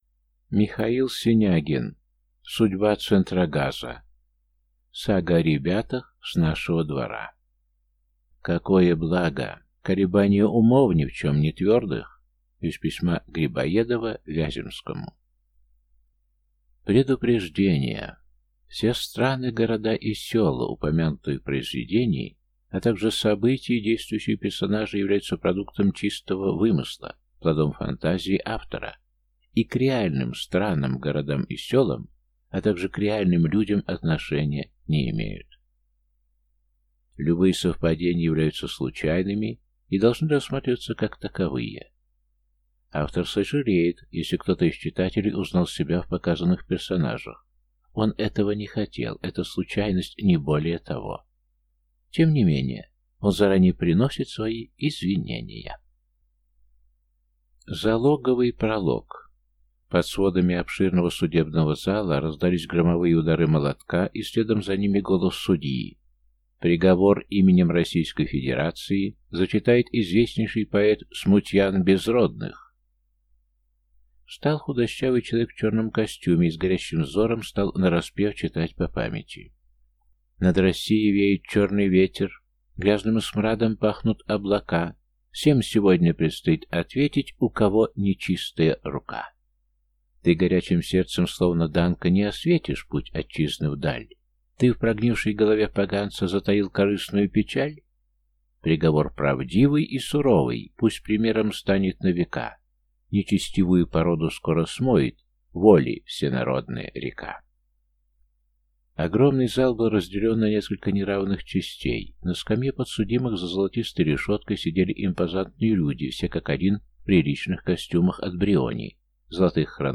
Аудиокнига Судьба Центрогаза. Сага о ребятах с нашего двора | Библиотека аудиокниг